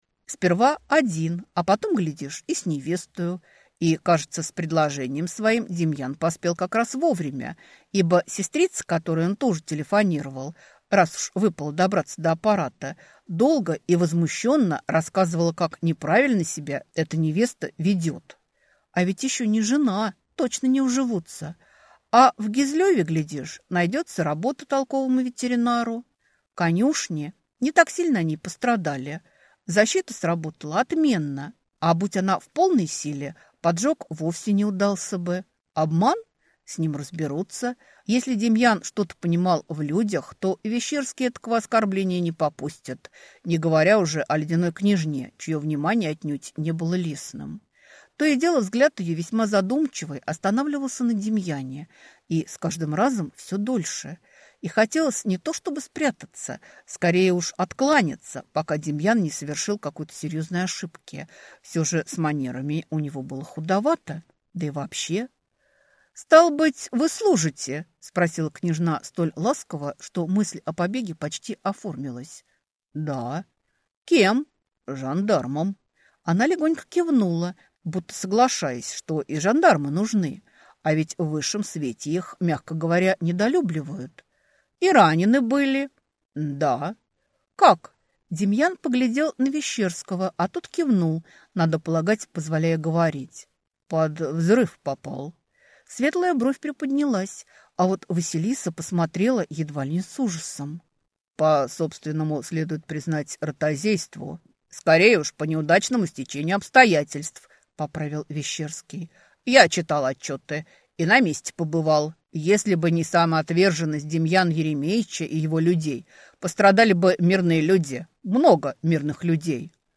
Аудиокнига Леди, которая любила лошадей | Библиотека аудиокниг